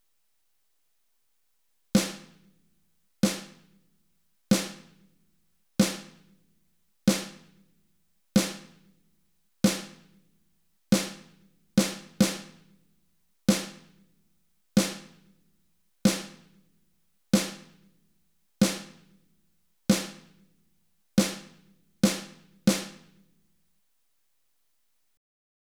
Te facilitamos algunas pistas de audio de diferentes instrumentos con las que podrás jugar y pasar un buen rato con tu programa de mezcla de sonido:
tambor.wav